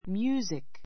mjúːzik